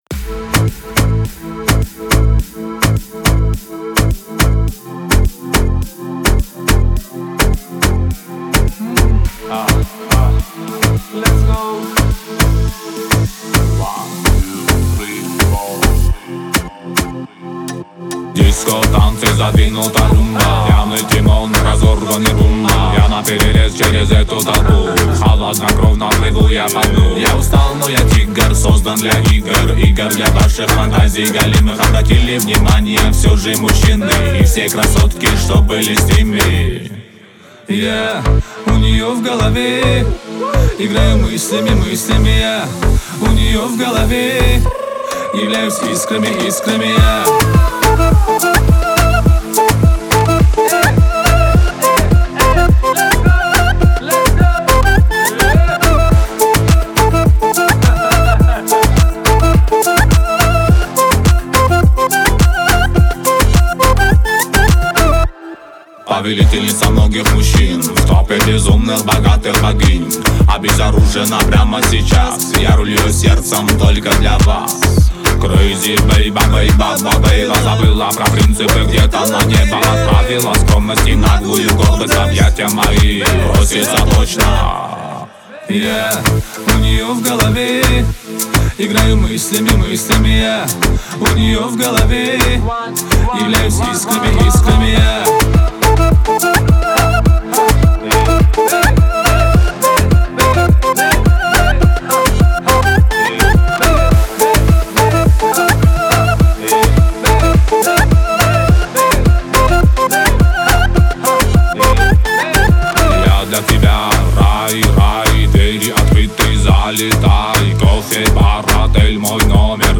поп-рэп